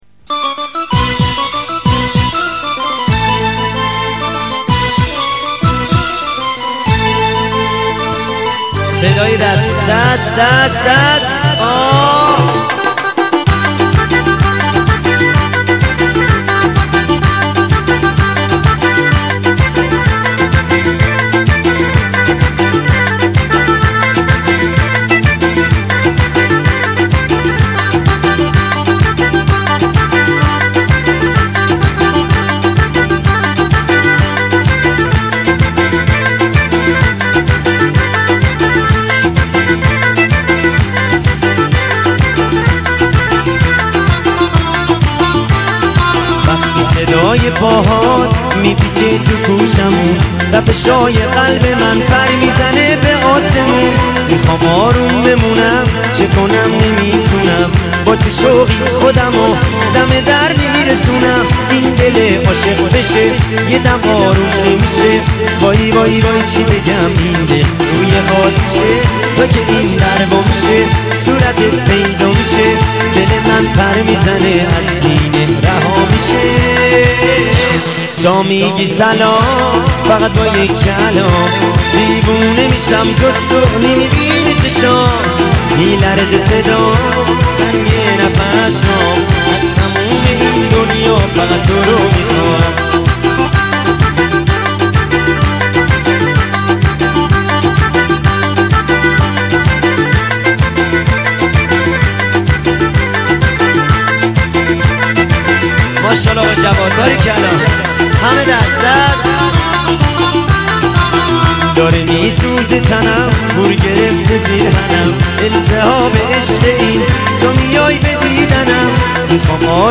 با صدای مرد ارکستی